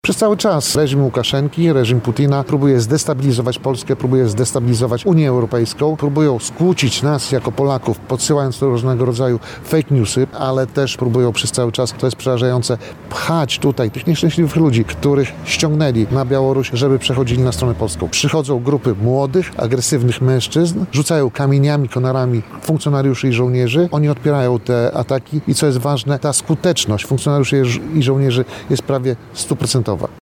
– Takie spotkania są dla mnie bardzo istotne – mówił tuż przed spotkaniem szef MSWiA, Tomasz Siemoniak.